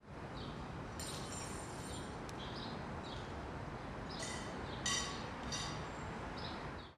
CSC-08-013-GV - Talheres Caindo ao Longe e Colocando Junto ao Prato.wav